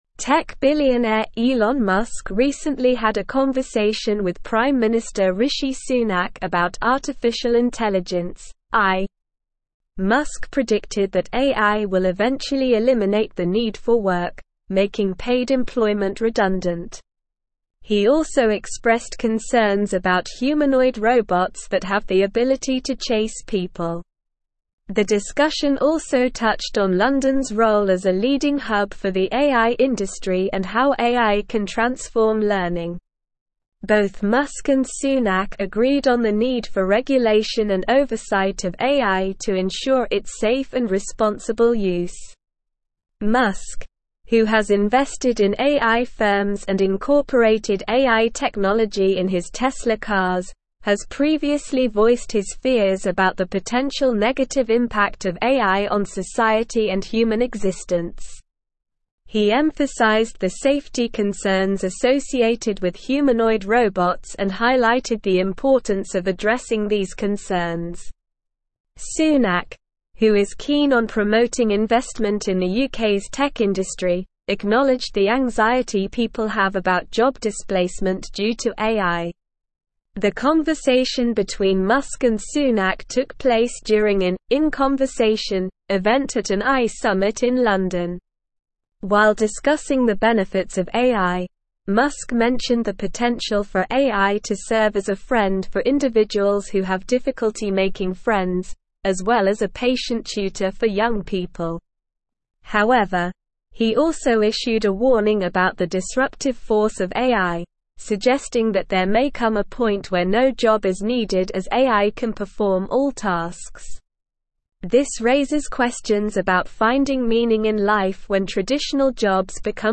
Slow
English-Newsroom-Advanced-SLOW-Reading-Elon-Musk-and-Rishi-Sunak-discuss-AIs-impact.mp3